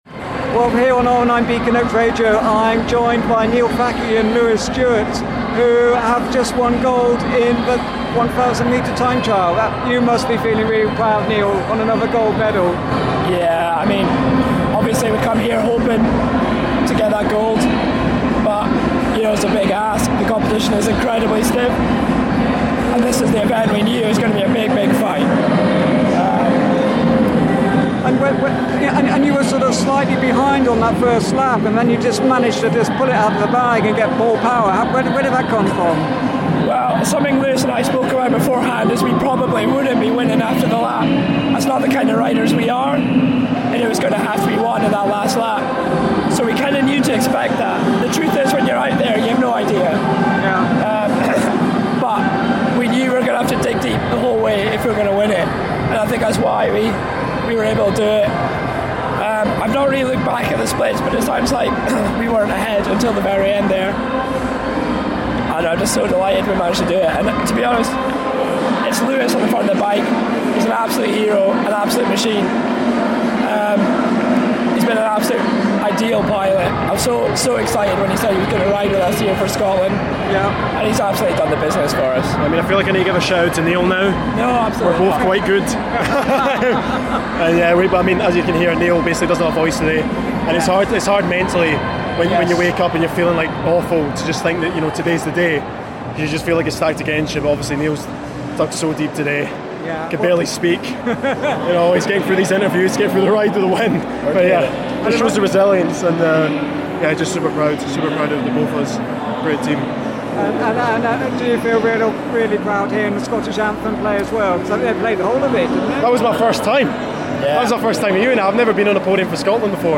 We're at the Commonwealth Games with our roving reporter